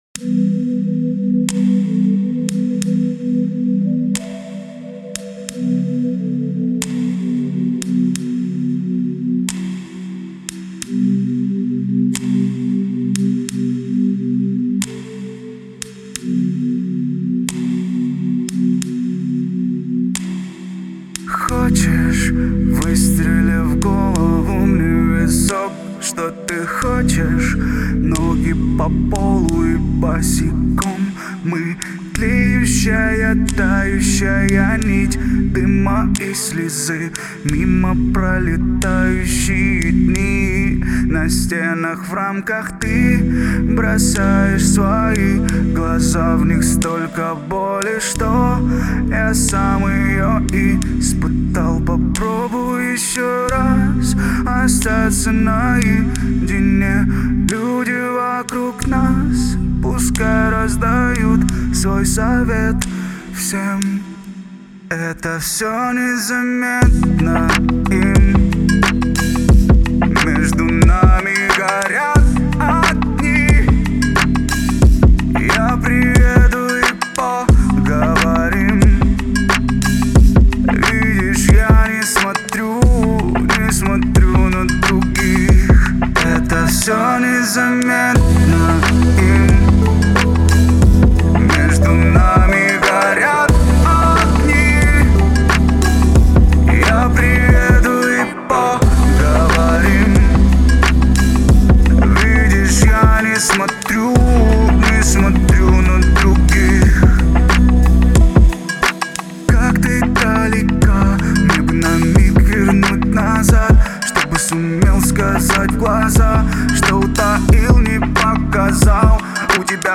это мелодичный трек в жанре поп-рок